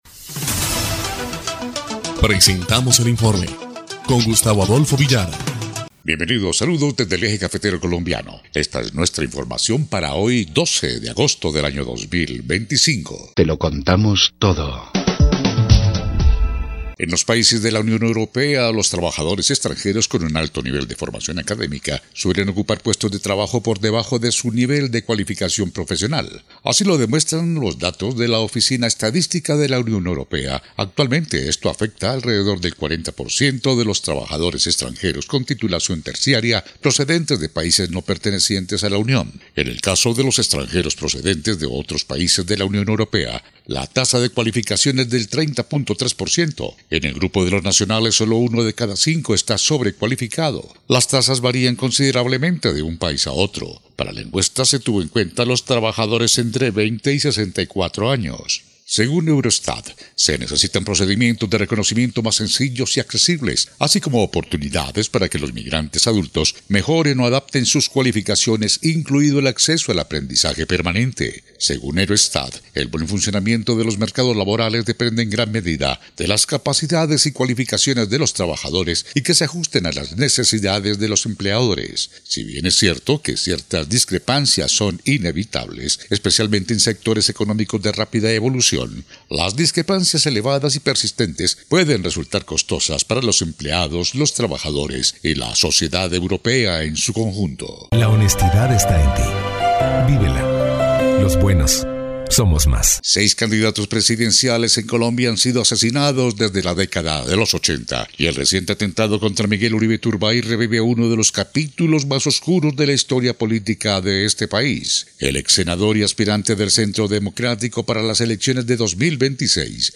EL INFORME 3° Clip de Noticias del 12 de agosto de 2025